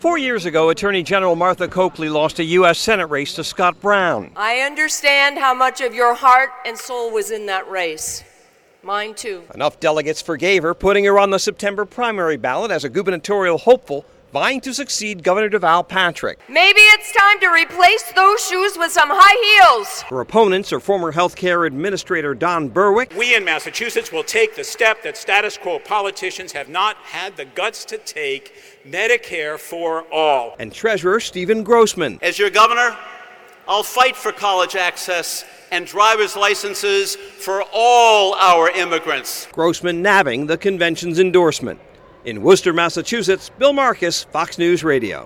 HAS MORE FROM WORCESTER, MASSACHUSETTS.